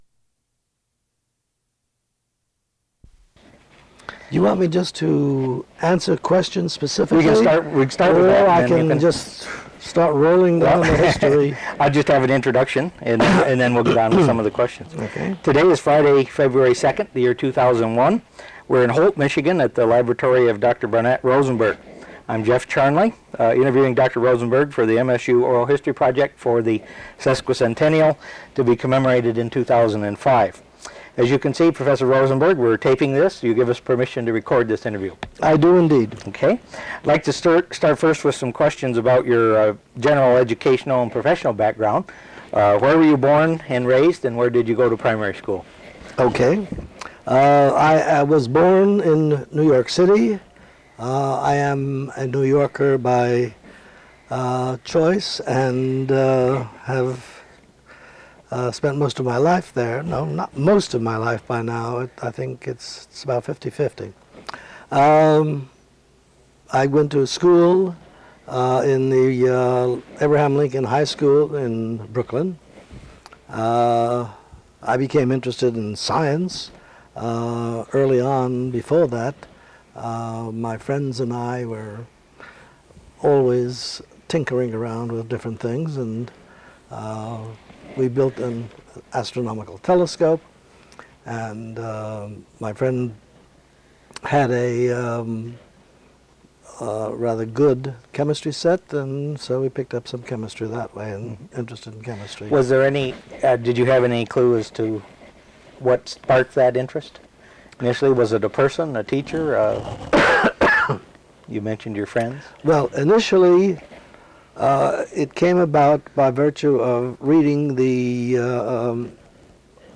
Original Format: Audiocassettes